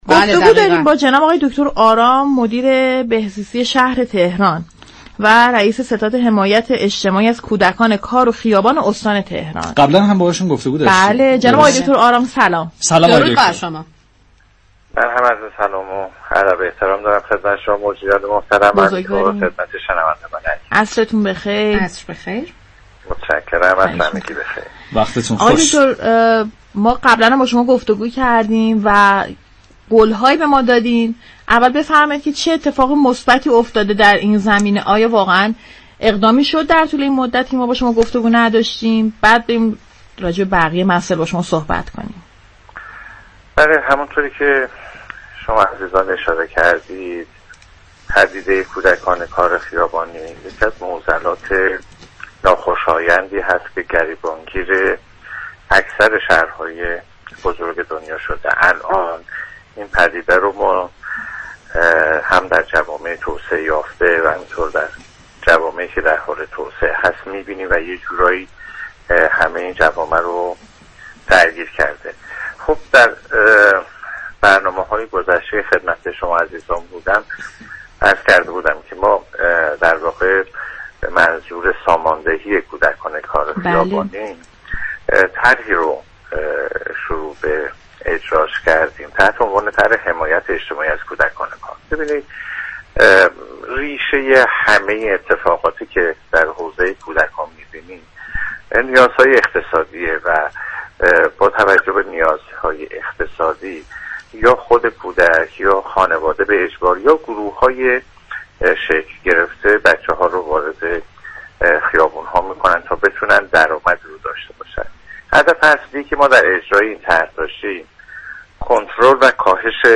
به گزارش پایگاه اطلاع رسانی رادیو تهران، سید سعید آرام مدیر بهزیستی شهر تهران در خصوص طرح اجتماعی حمایت از كودكان كار و خیابانی در گفتگو با فرحزاد رادیو تهران، گفت: پدیده كودكان كار خیابانی معضلی است كه گریبان‌گیر جوامع توسعه‌یافته و جوامع درحال‌توسعه است.